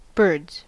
Ääntäminen
US : IPA : [bɝdz]